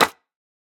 Minecraft Version Minecraft Version 25w18a Latest Release | Latest Snapshot 25w18a / assets / minecraft / sounds / block / mud_bricks / step4.ogg Compare With Compare With Latest Release | Latest Snapshot
step4.ogg